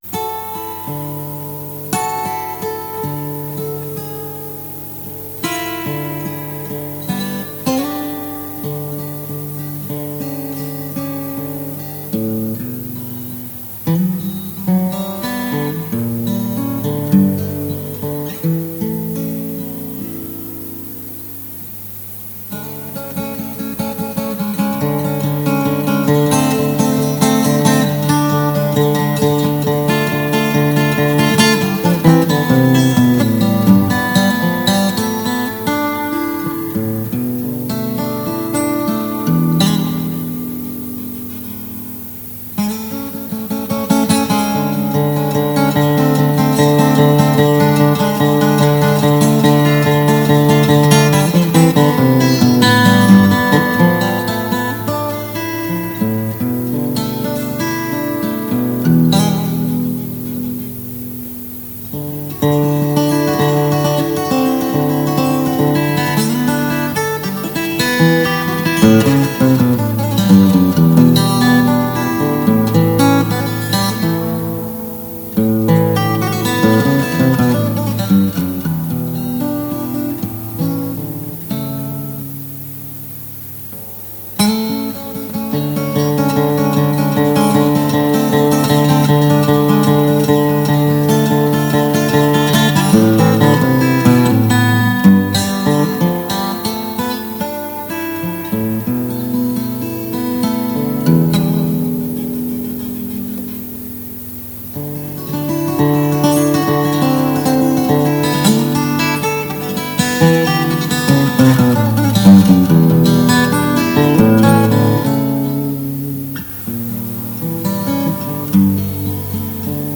An instrumental I’m revisiting.
I like the execution of this old version, but it’s not well-recorded.